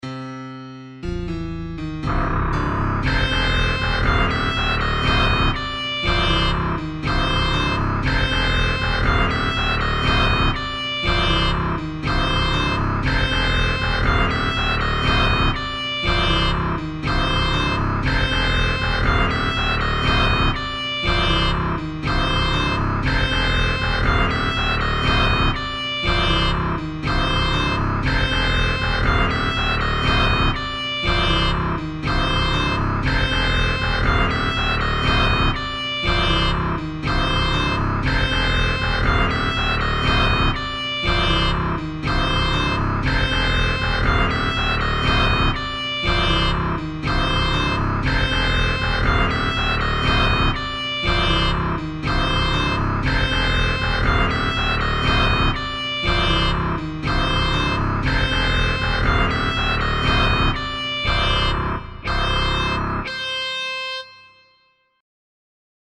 гитара
без слов
яркие
нагнетающие
Очень яркий.